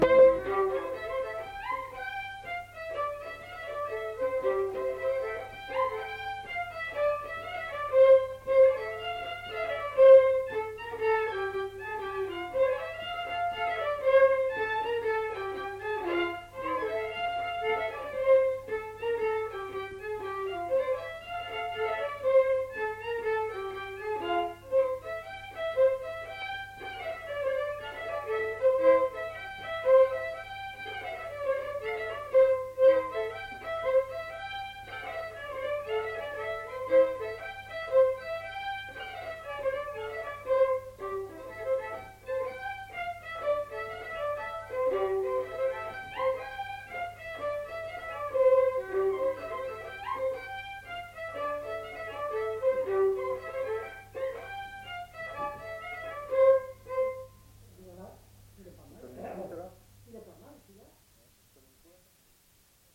Aire culturelle : Lomagne
Genre : morceau instrumental
Instrument de musique : violon
Danse : rondeau
Notes consultables : Morceau en trois parties. Deux violons.